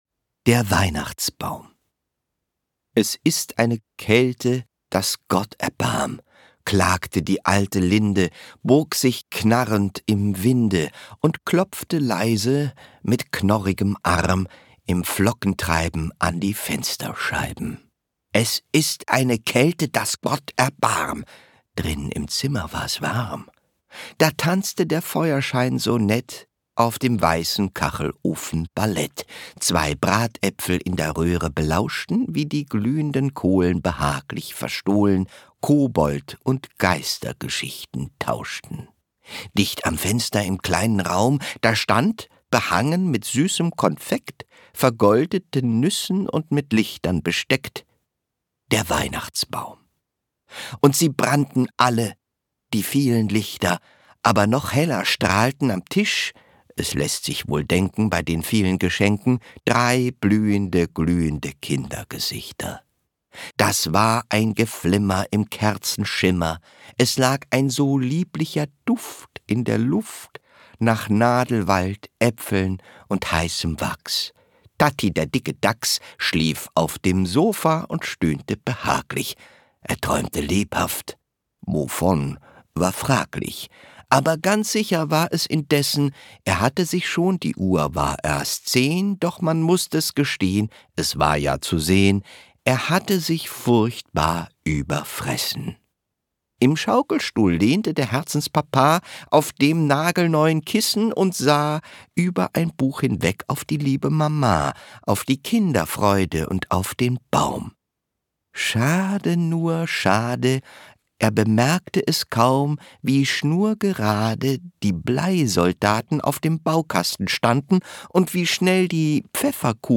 Lesung mit Matthias Matschke (1 CD)
Matthias Matschke (Sprecher)